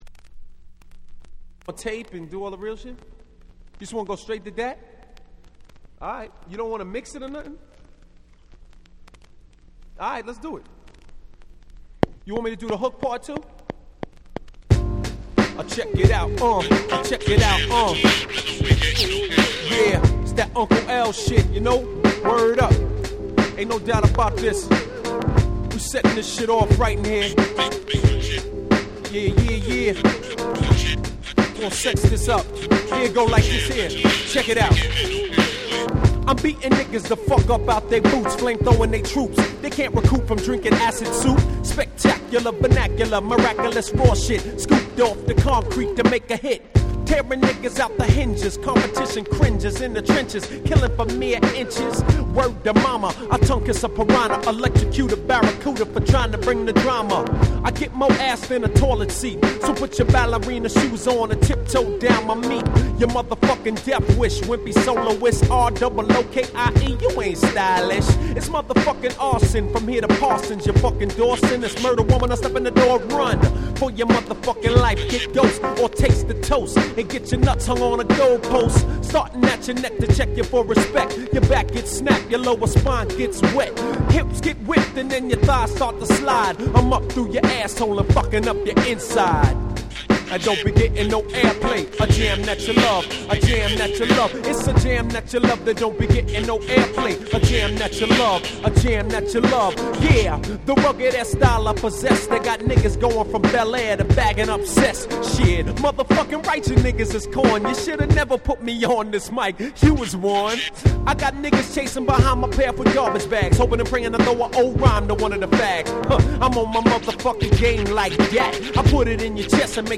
95' Very Nice Hip Hop !!
音質も大丈夫ですのでプロモをお持ちの方もプレイ用に是非！！
Boom Bap ブーンバップ